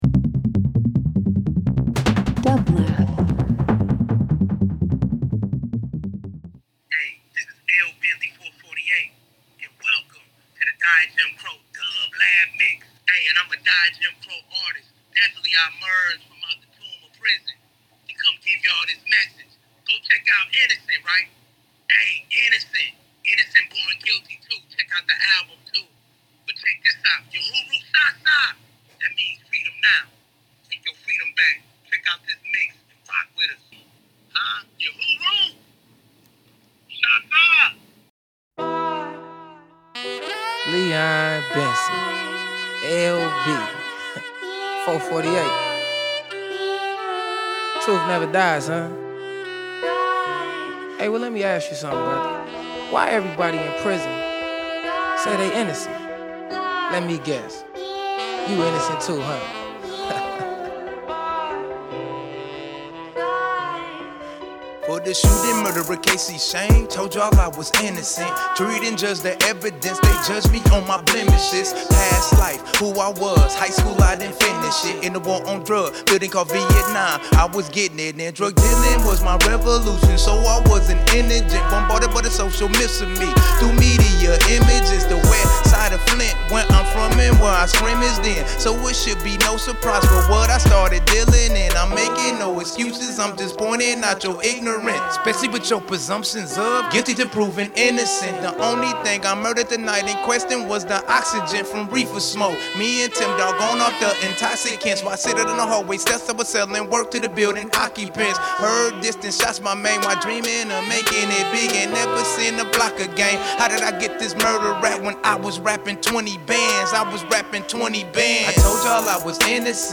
Hip Hop R&B Soul